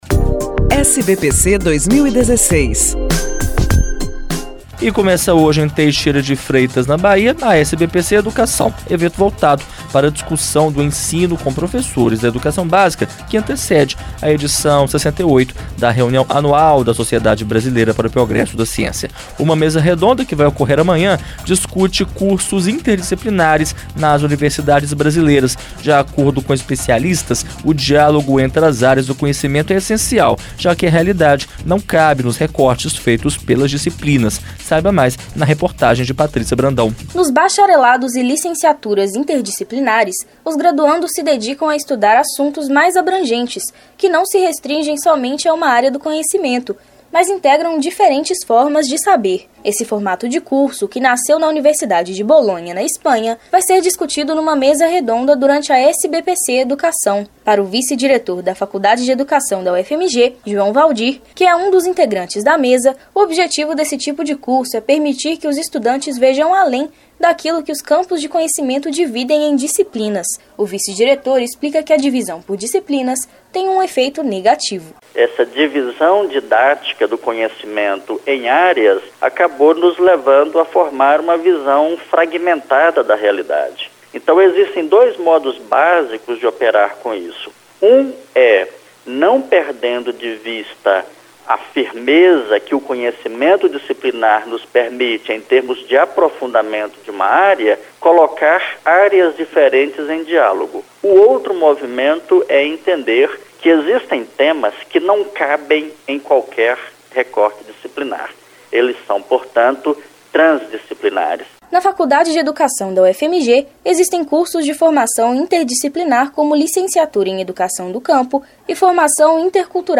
As edições especiais do programa tiveram início na última sexta-feira, 1º, com entrevista com a presidente da SBPC, Helena Nader, e